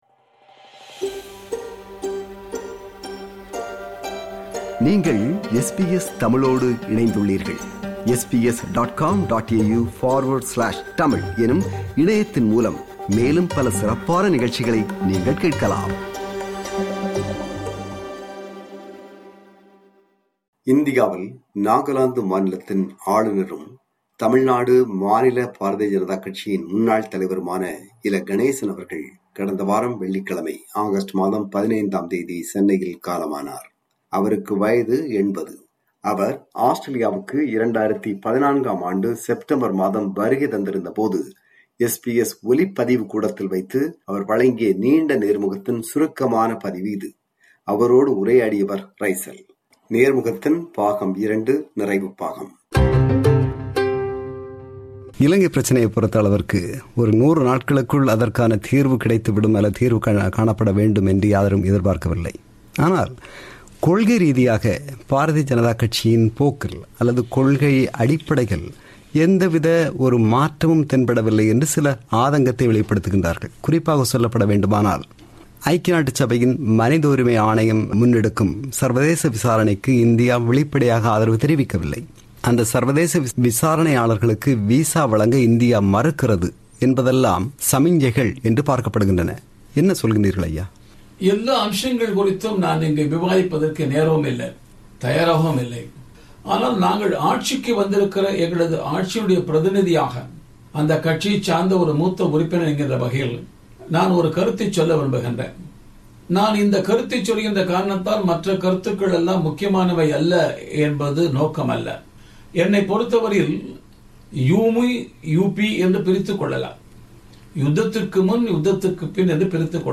அவர் ஆஸ்திரேலியாவுக்கு 2014 செப்டம்பர் மாதம் வருகை தந்திருந்தபோது SBS ஒலிப்பதிவு கூடத்தில் வைத்து வழங்கிய நீண்ட நேர்முகத்தின் சுருக்கமான பதிவு.
நேர்முகம் பாகம் 2.